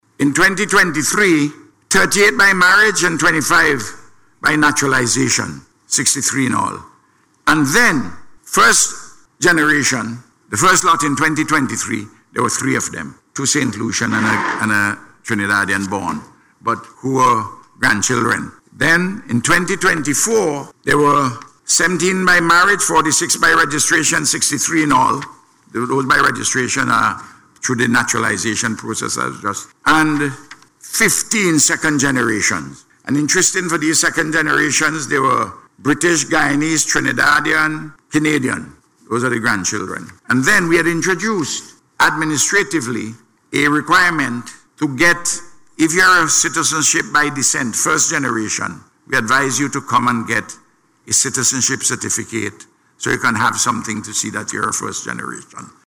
Speaking in Parliament yesterday, the Prime Minister said in 2019, 74 persons were granted citizenship.